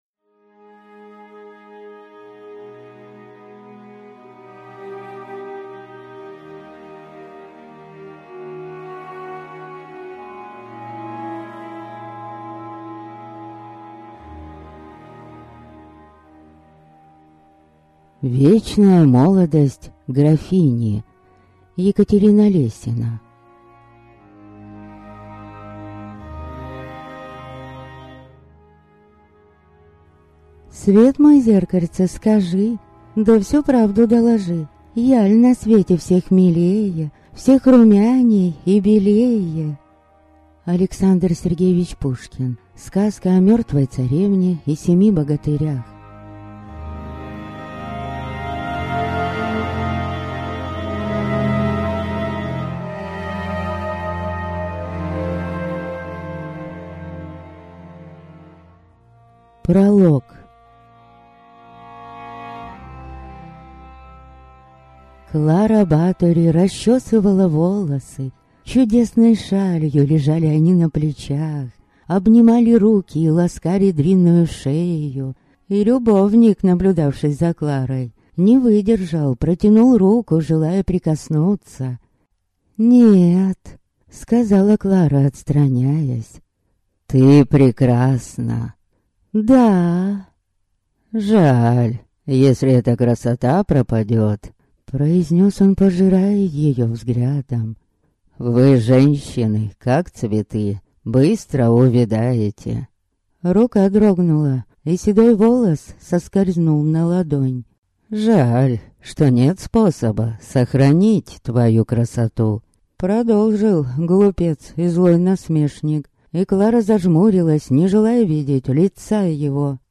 Аудиокнига Вечная молодость графини | Библиотека аудиокниг